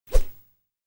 sfx_woosh_2.mp3